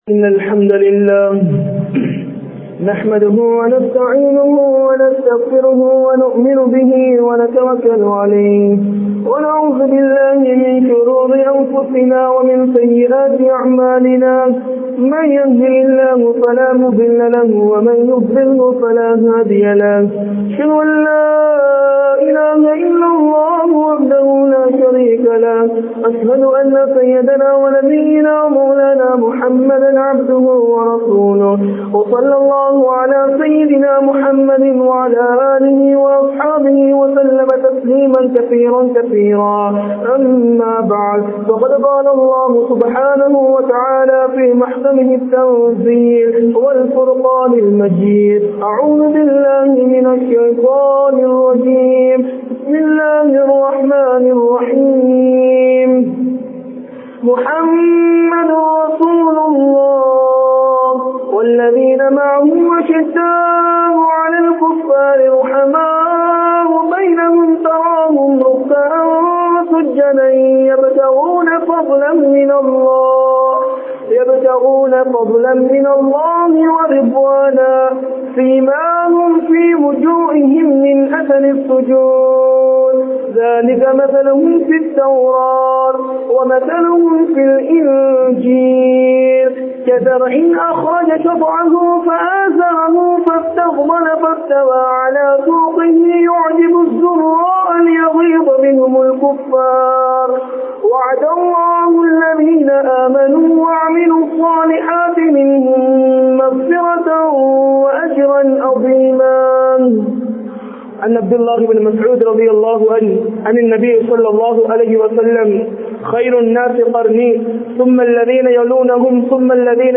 Shahabakkalin Mun Maathirihal (ஸஹாபாக்களின் முன்மாதிரிகள்) | Audio Bayans | All Ceylon Muslim Youth Community | Addalaichenai
Majma Ul Khairah Jumua Masjith (Nimal Road)